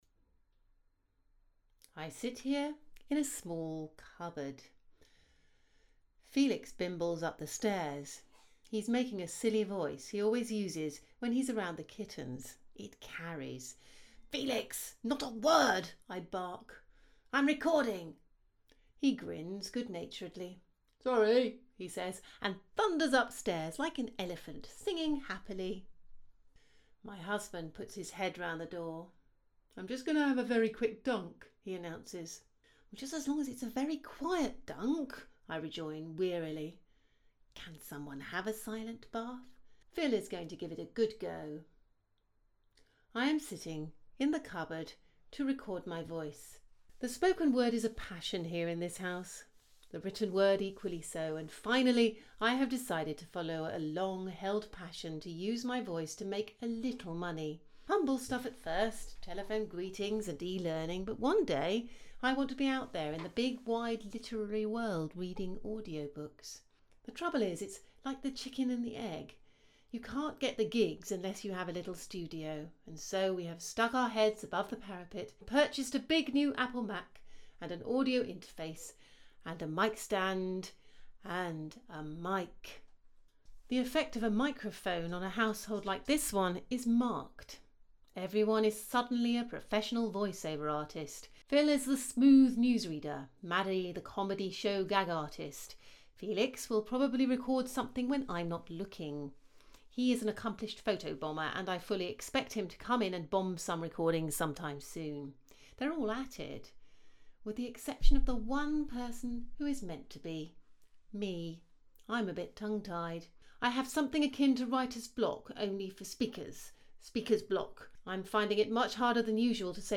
You can listen to the results (minus any soundproofing and a studio door) here:
You have such a rich, classy, high-falutin’ voice, like the queen or at least a duchess.